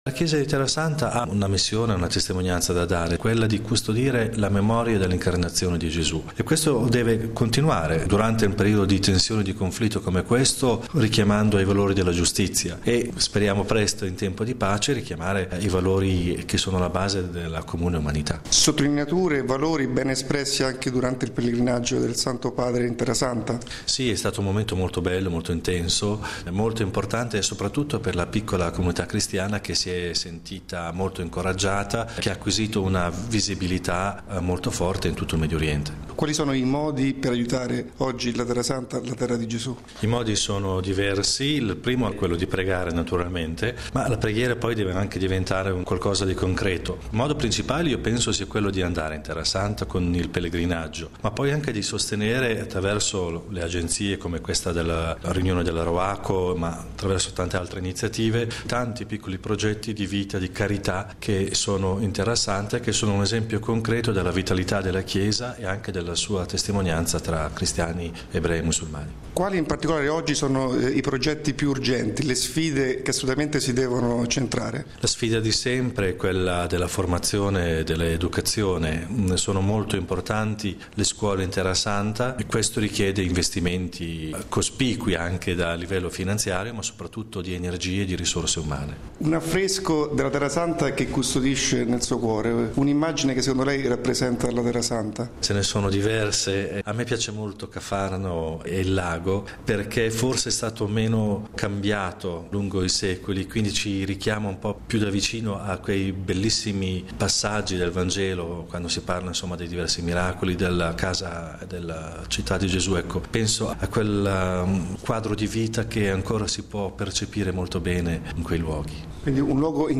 Intervista con mons.